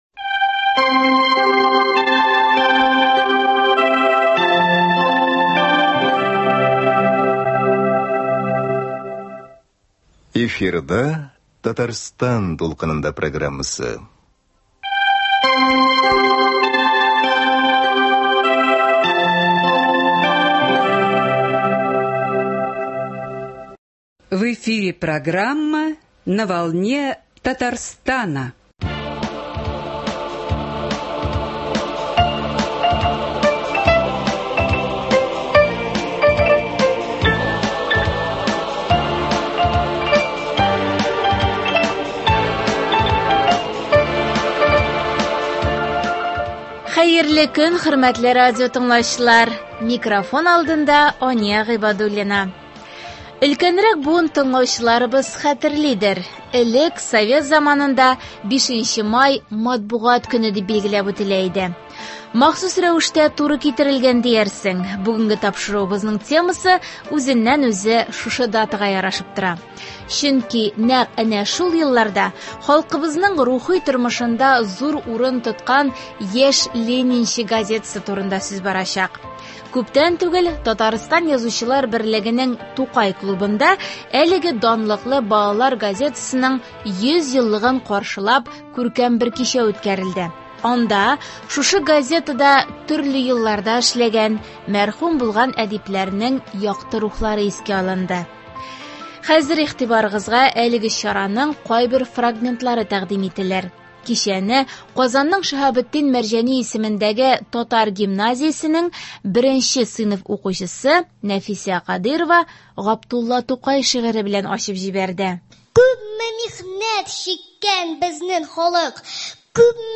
“Балачак иленә сәяхәт”. “Яшь ленинчы” газетасы оешуга 100 ел тулуга багышланган кичәдән репортаж.
Хәзер игтибарыгызга әлеге чараның кайбер фрагментлары тәкъдим ителер.